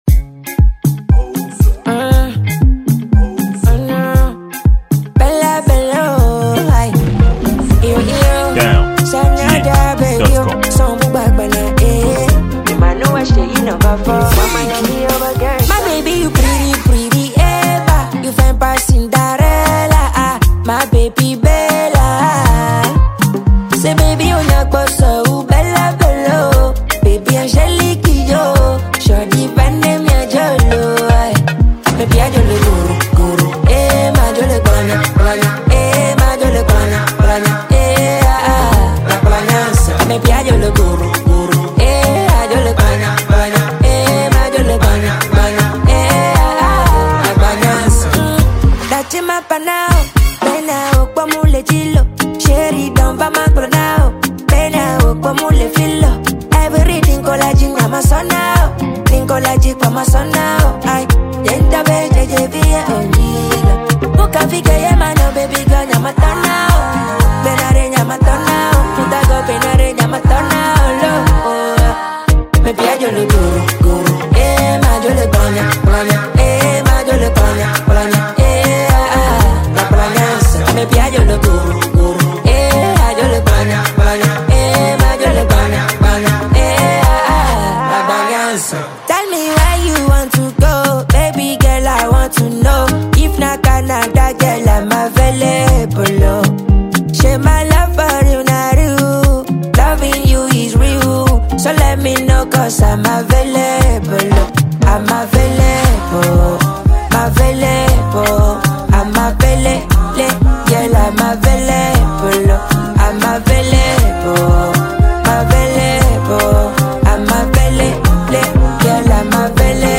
Nigerian musician